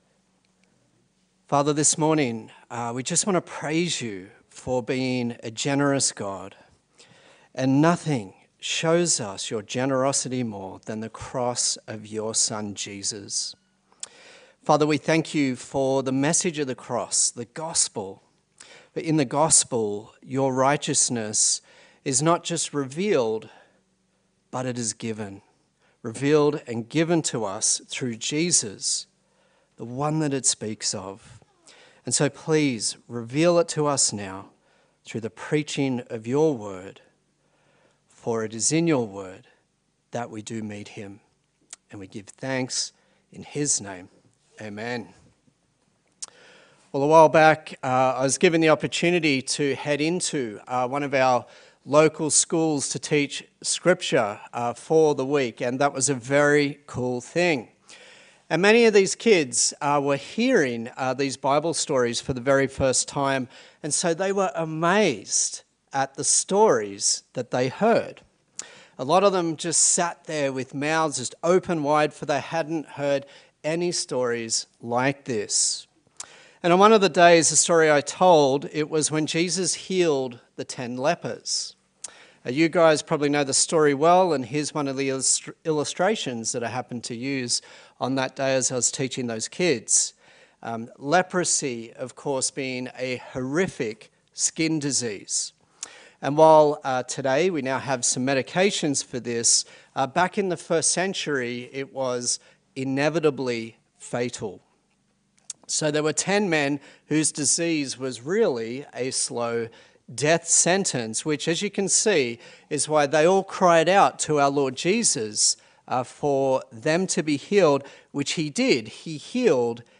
Galatians Passage: Galatians 3:1-14 Service Type: Sunday Service